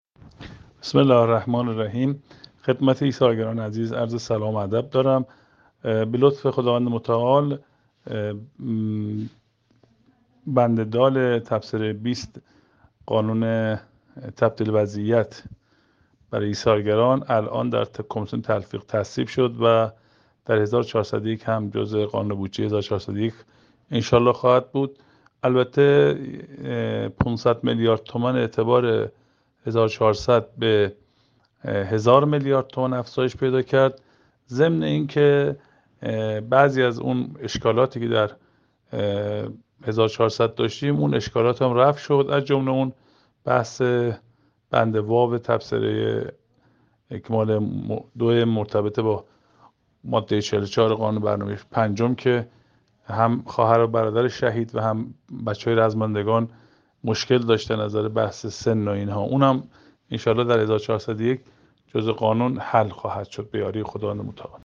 امیرآبادی رئیس فراکسیون ایثارگران مجلس شورای اسلامی در بخشی از سخنانش گفته است: